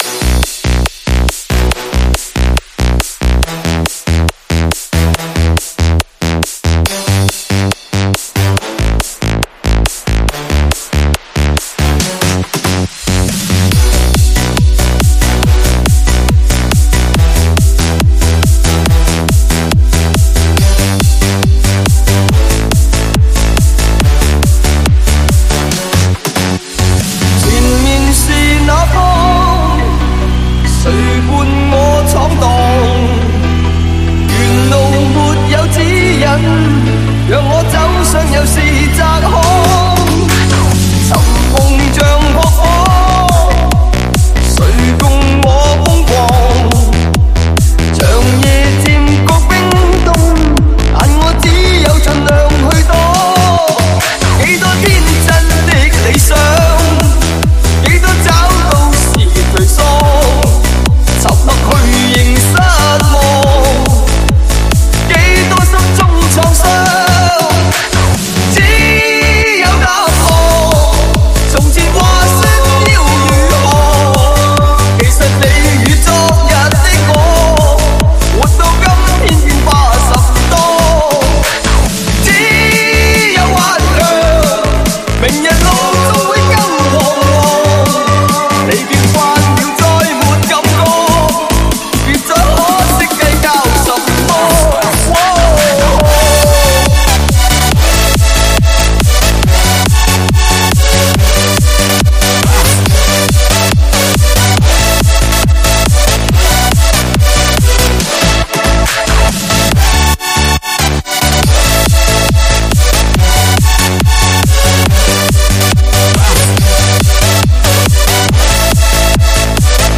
试听文件为低音质，下载后为无水印高音质文件 M币 4 超级会员 M币 2 购买下载 您当前未登录！